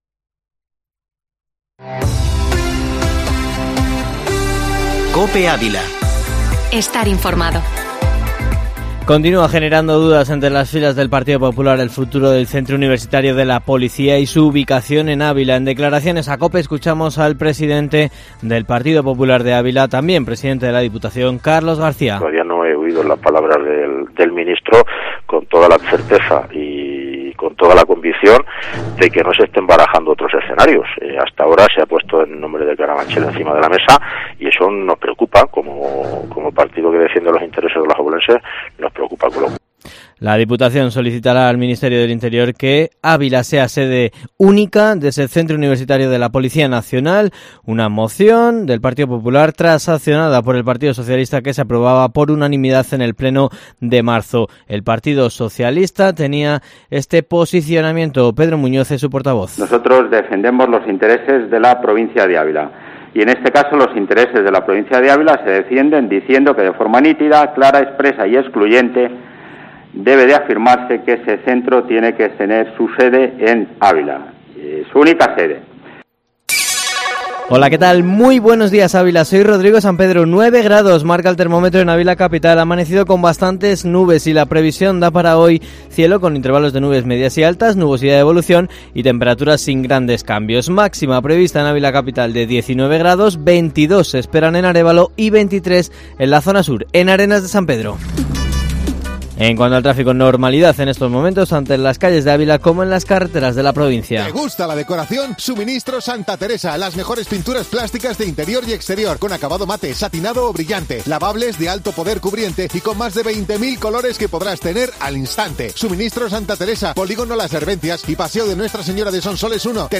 Informativo matinal Herrera en COPE Ávila 30/03/2021